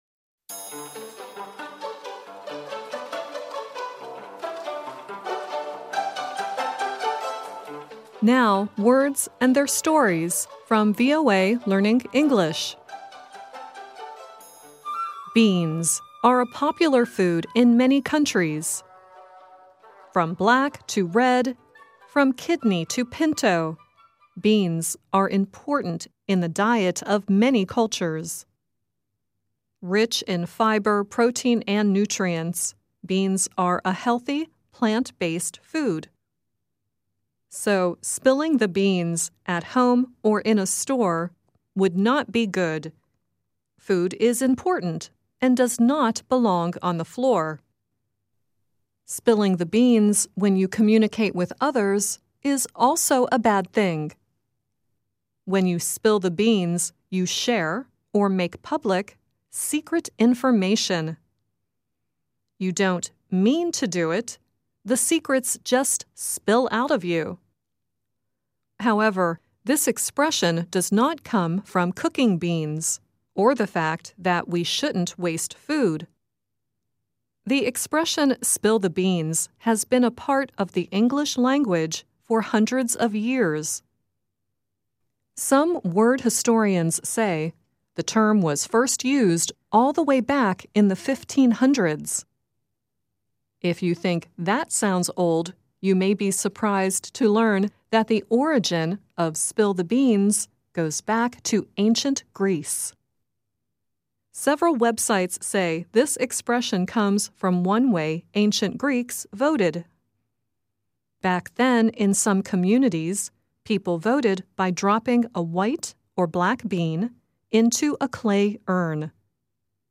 The song at the end is Paul Kelly singing "Don't Start Me Talking."